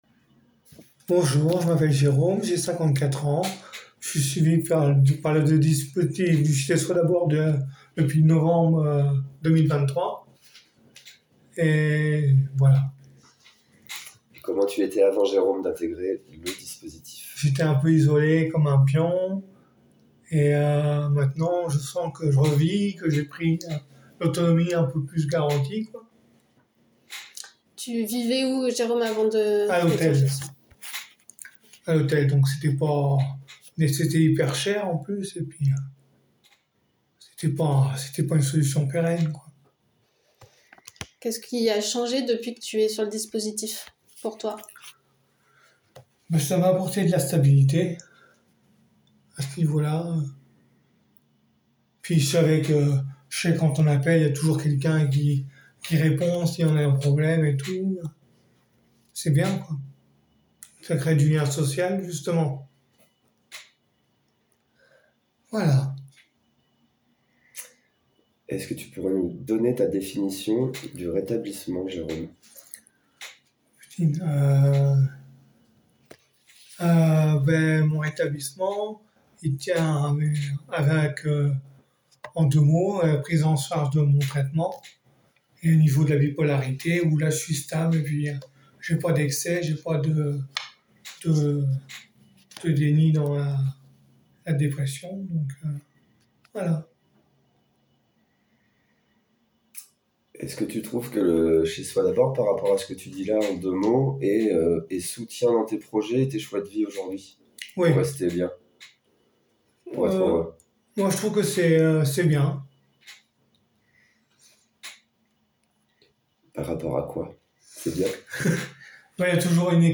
Témoignage audio d'une personne accompagnée m4a - 2.9 Mio
temoignage-personne-accompagnee.m4a